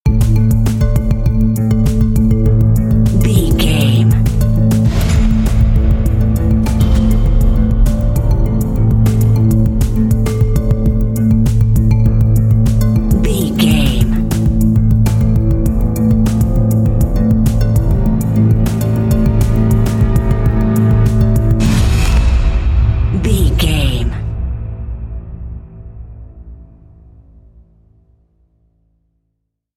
Thriller
Aeolian/Minor
bass guitar
synthesiser
piano
drum machine